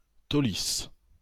Taulis (French pronunciation: [tolis]